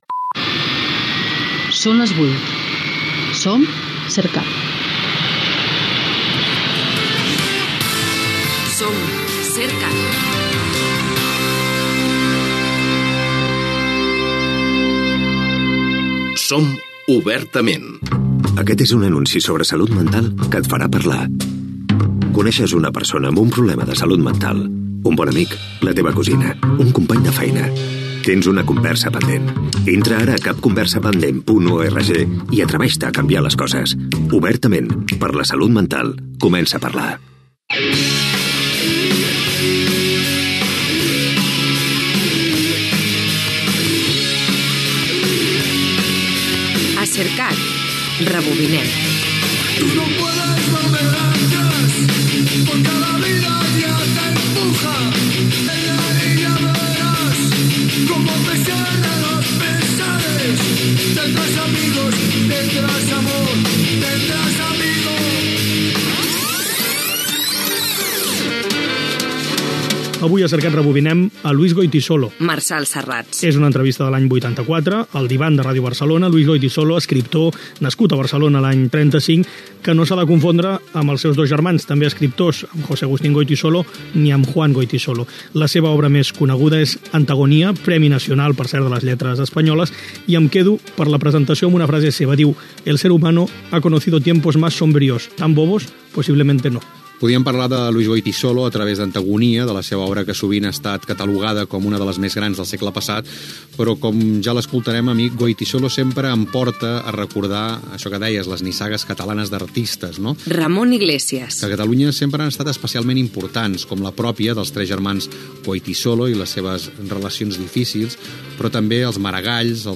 Hora, identificació de l'emissora, publicitat, careta del programa i presentació de la biografia de Luis Goytisolo prèvia al programa "El Diván" de 1984.
Entreteniment
FM